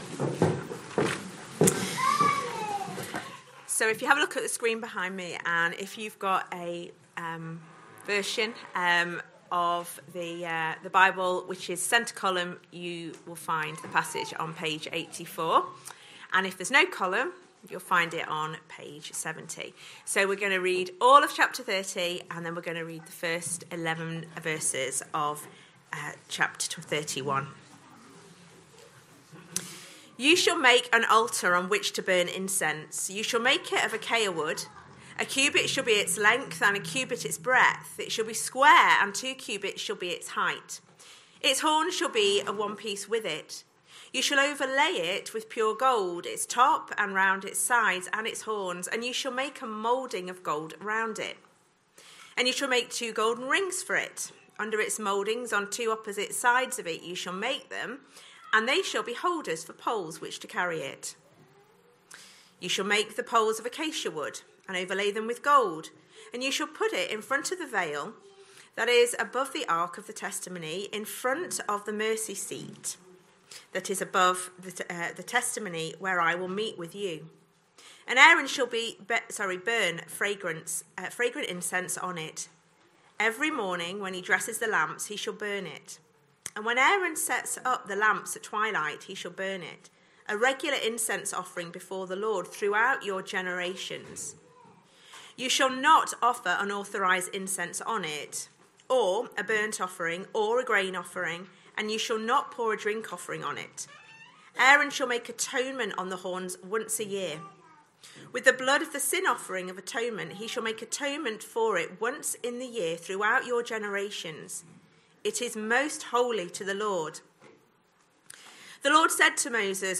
Sunday AM Service Sunday 19th October 2025 Speaker